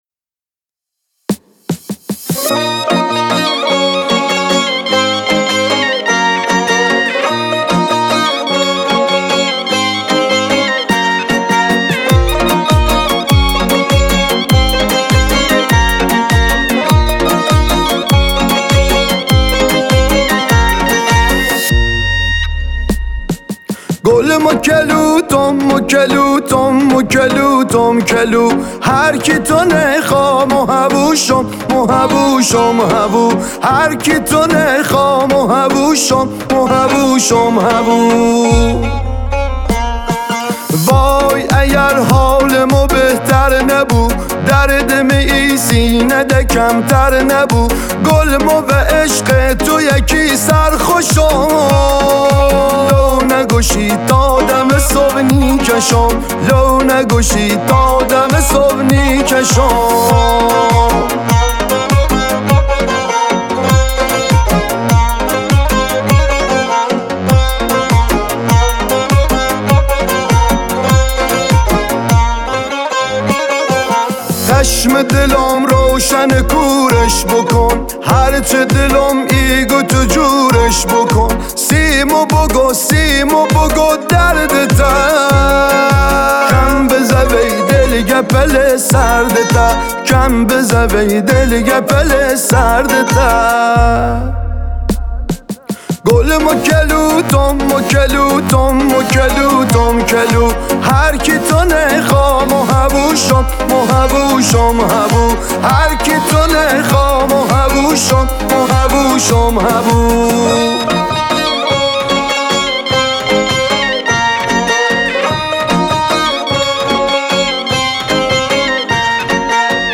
اهنگ لری